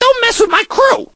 crow_start_03.ogg